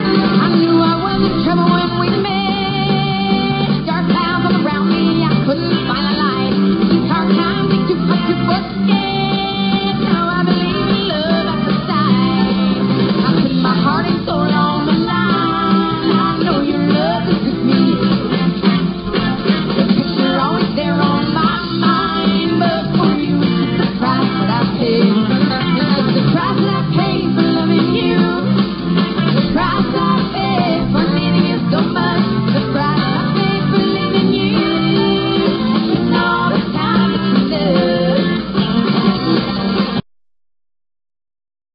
CONCERT CLIPS